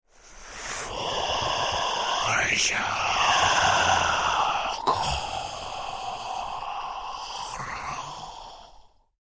Звук злодея, читающего заклинание